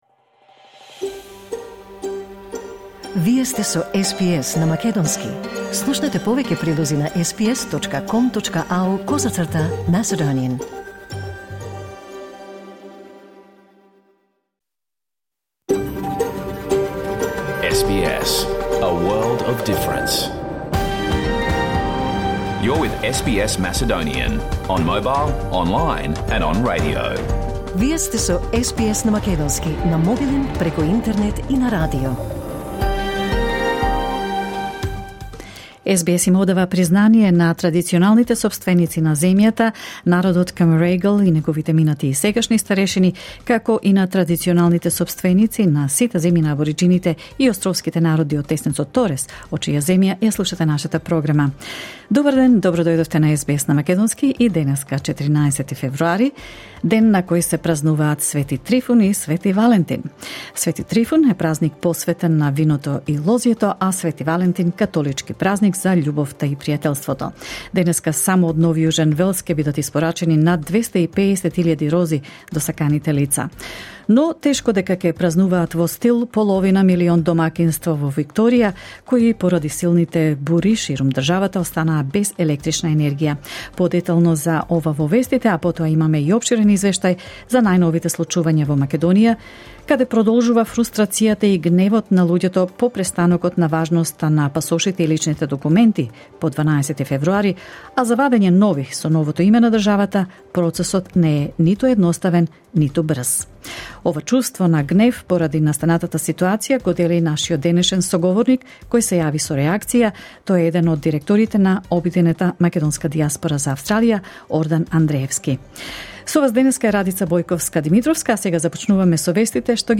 SBS Macedonian Program Live on Air 14 February 2024